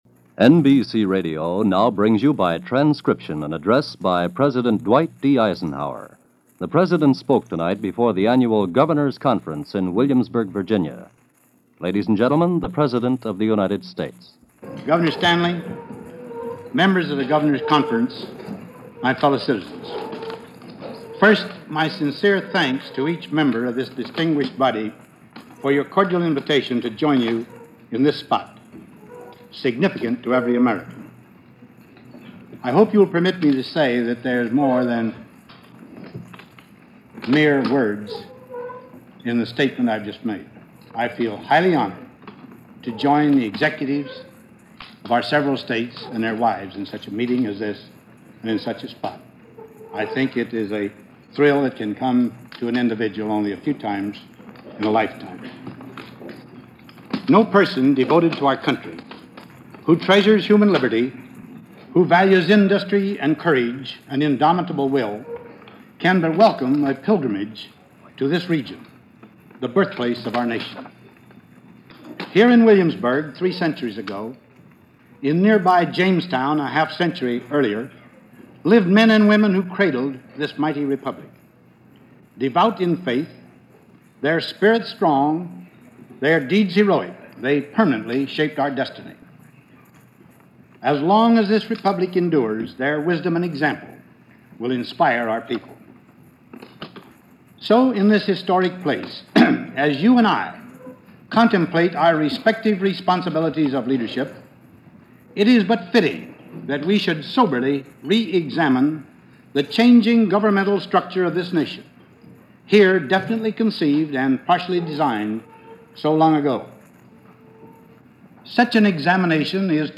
President Eisenhower Address to 1957 Governors Conference.
President Eisenhower Addresses 1957 Governors Conference - June 24, 1957 - President Eisenhower at the annual Governors Conference in Williamsburg Virginia